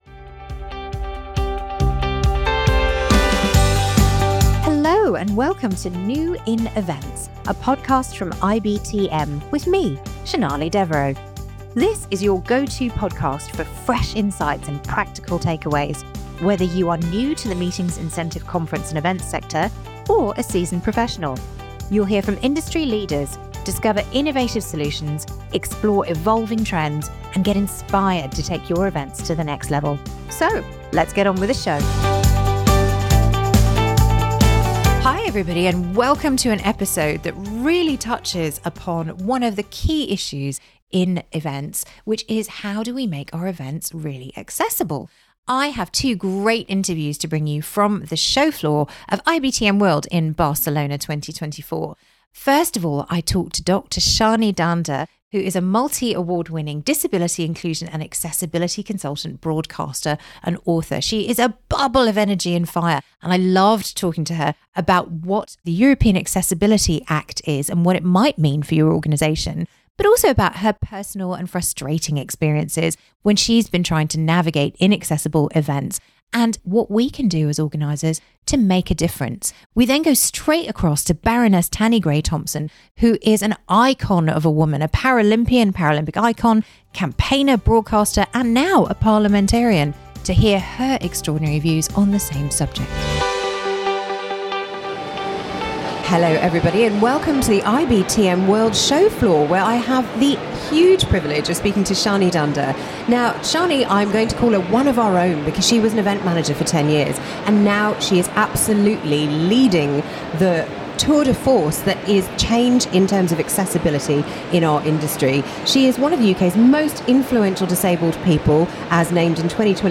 Both guests share their lived experiences of day-to-day life with a disability and offer valuable advice for how planners can better design events with accessibility at the forefront.